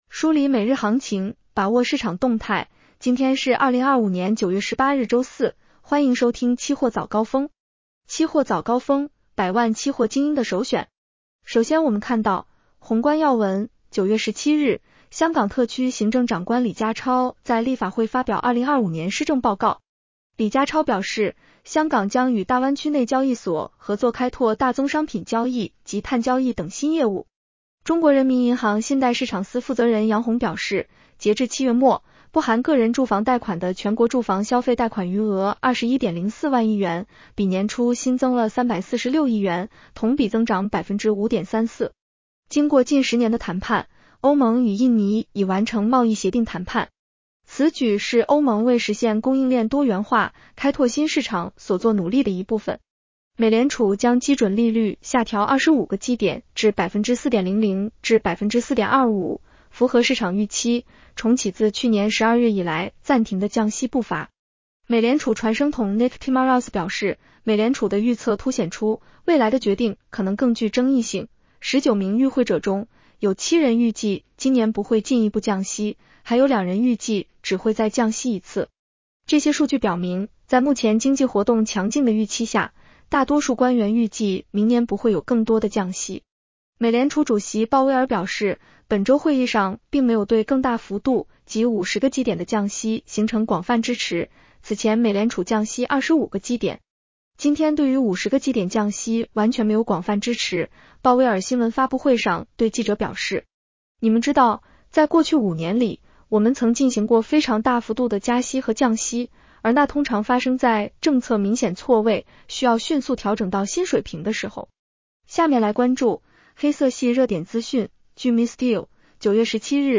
期货早高峰-音频版 女声普通话版 下载mp3 宏观要闻 1. 9月17日，香港特区行政长官李家超在立法会发表2025年《施政报告》。